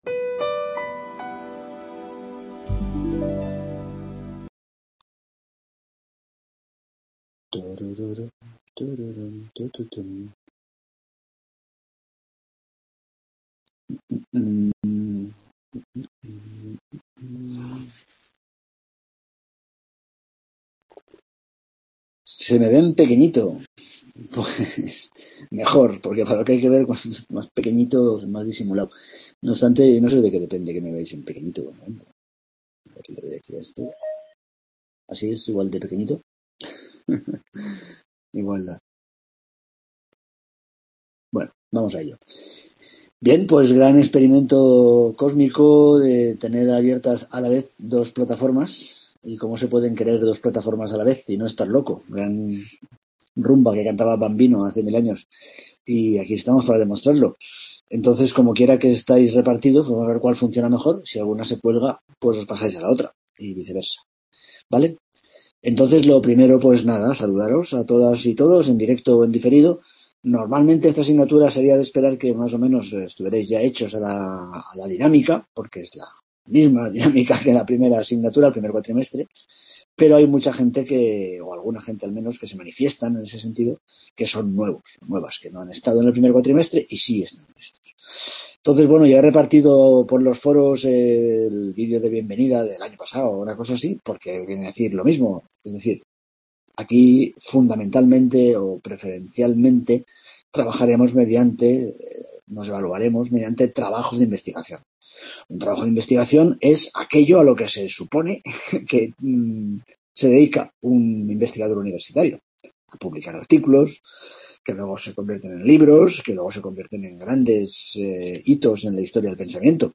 Primera clase y presentacion de Teoria del Arte 2 | Repositorio Digital